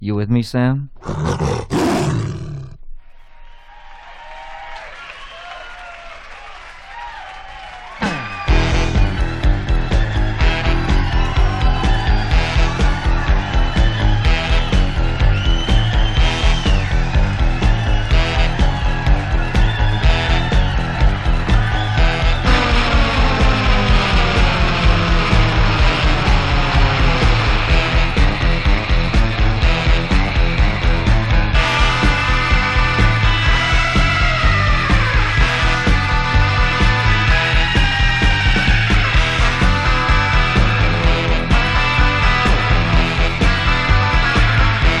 終始ゴキゲンなロックンロールを展開した痛快なライブ盤。
虎愛も、ギター愛も、ロック魂も炸裂する狂熱のライブ。
Rock, Surf　USA　12inchレコード　33rpm　Stereo